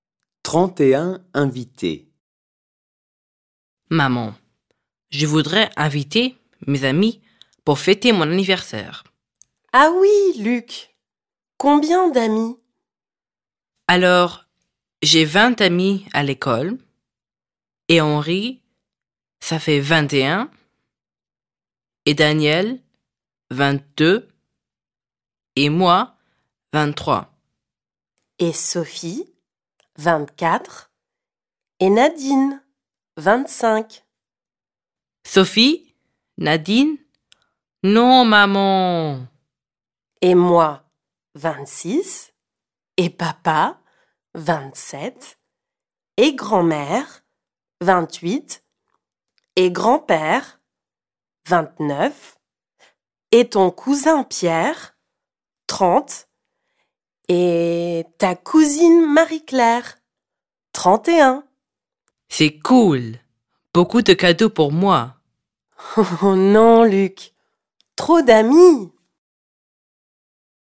Listen to the story 'Trente et un invités' performed by native French speakers